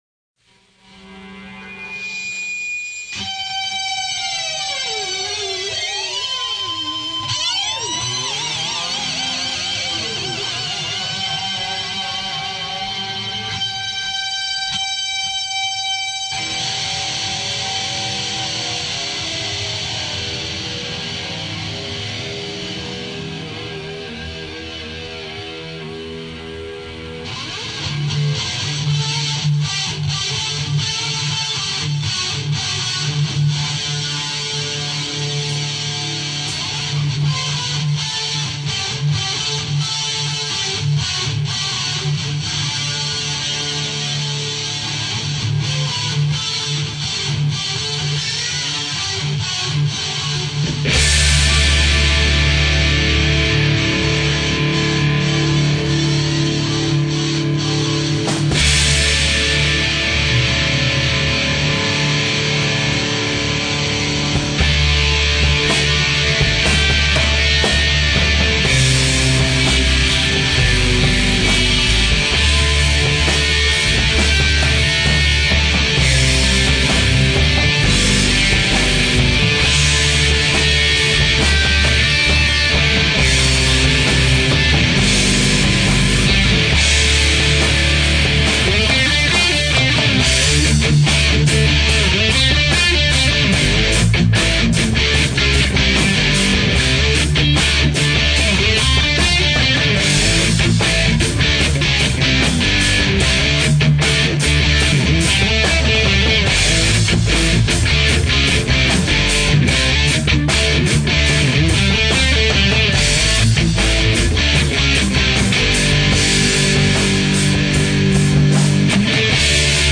Some of these recordings are from our own Studio, some recorded live at events with permission from the artists.
BREAD-JAM.mp3